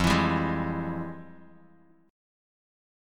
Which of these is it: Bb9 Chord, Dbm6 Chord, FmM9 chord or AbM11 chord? FmM9 chord